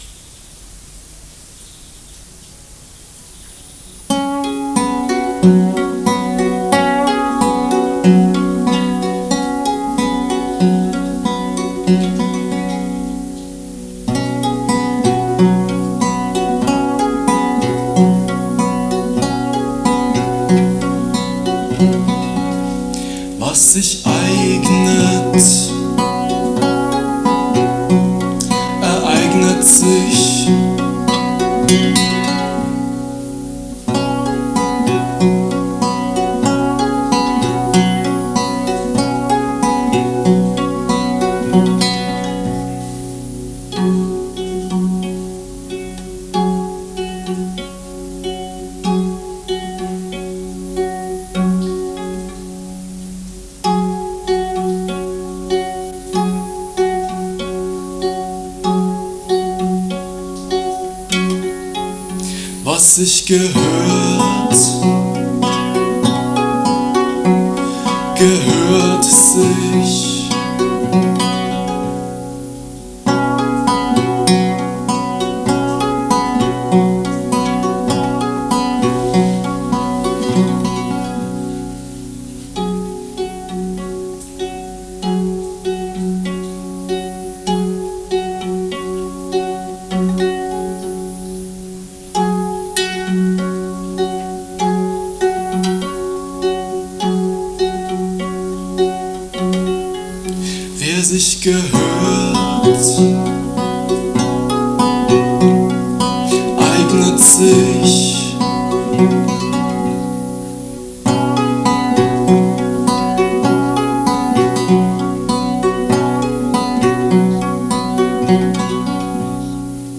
live 10.07.1993 ibp München)Herunterladen